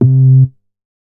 MoogSubDown B.WAV